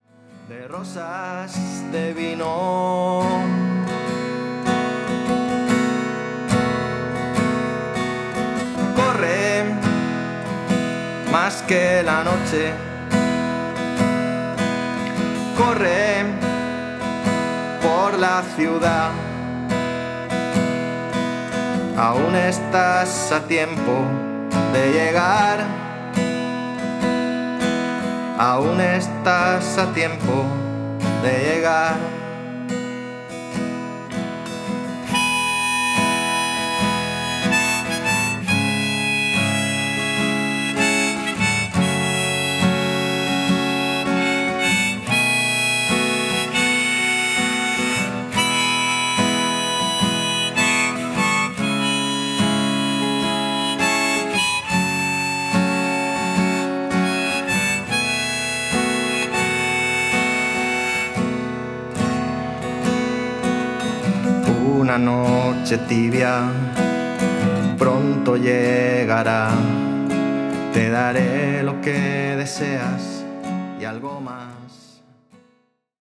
SINGER SONGWRITER
Recording Acoustic Live